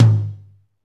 Index of /90_sSampleCDs/Northstar - Drumscapes Roland/DRM_Medium Rock/KIT_M_R Kit 2 x
TOM M R H0WR.wav